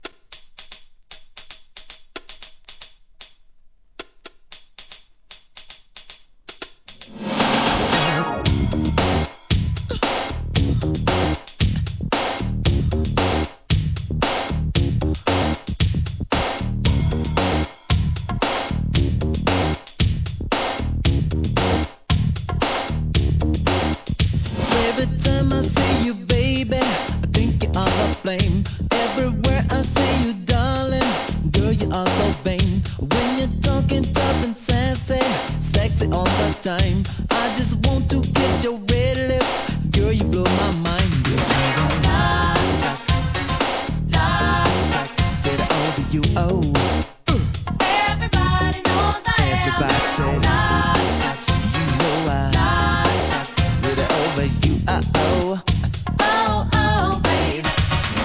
¥ 880 税込 関連カテゴリ SOUL/FUNK/etc...
アタック感の強いビートはインパクト大‼